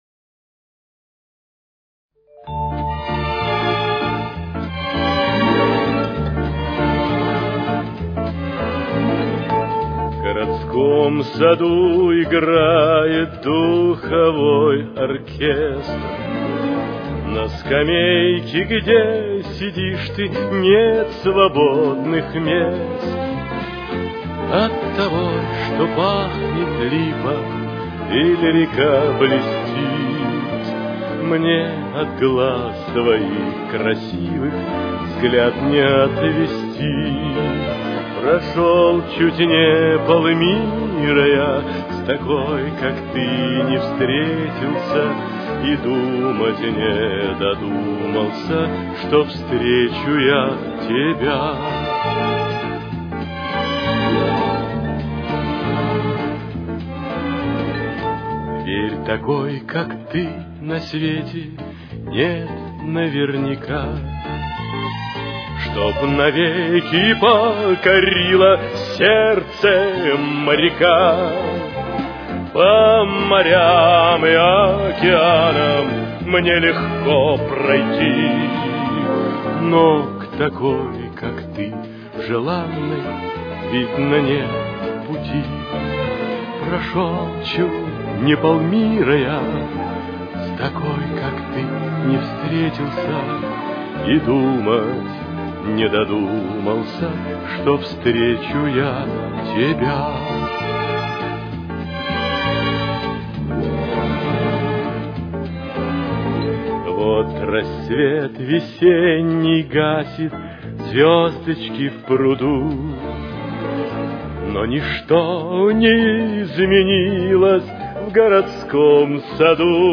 Ре минор. Темп: 208.